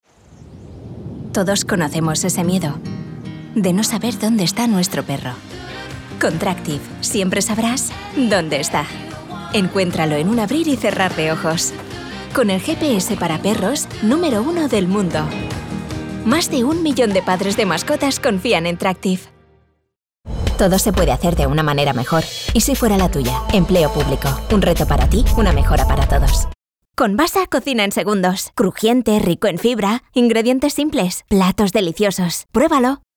Jong, Natuurlijk, Veelzijdig, Warm, Zacht
Commercieel
A versatile voice that can reach audiences with a sweet, natural and friendly vibe, she can also bring an emotional depth, or perhaps a serious and mature tone for business presentations.